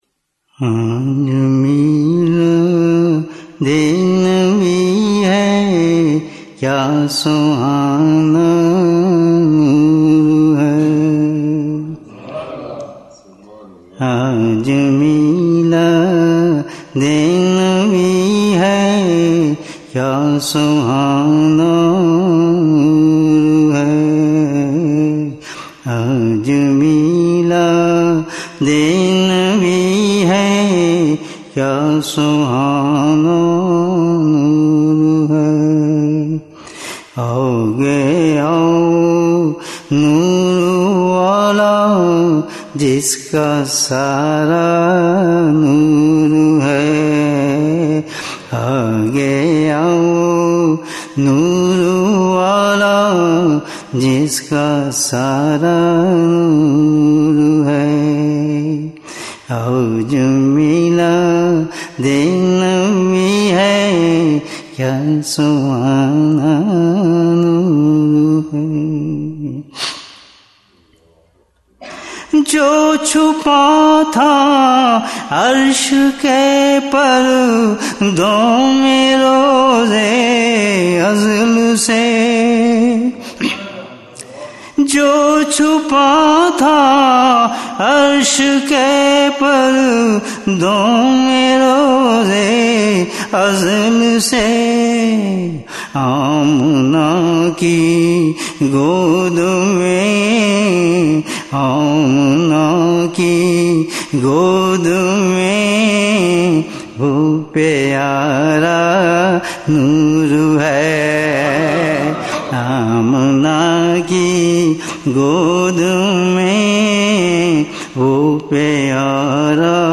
Naat
Amazing voice Masha’Allah